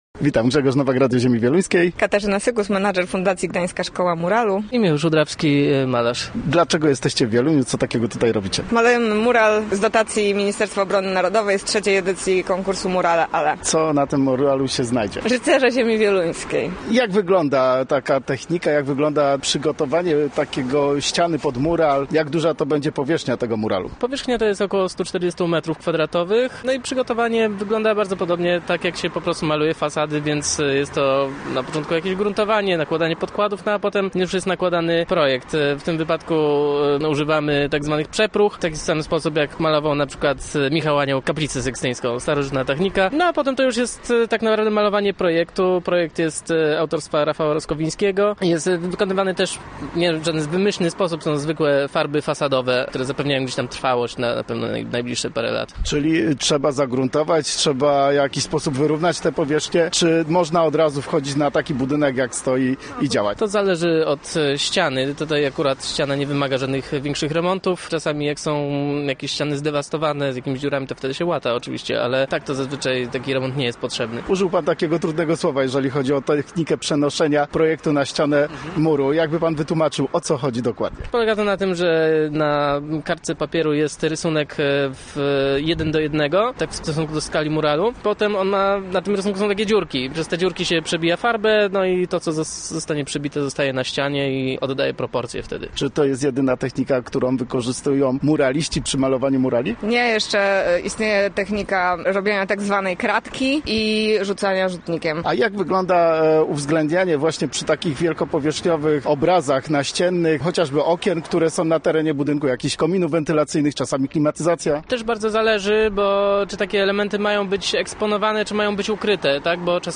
Gośćmi Radia ZW byli członkowie Fundacji Gdańska Szkoła Muralu